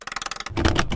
clamp3.wav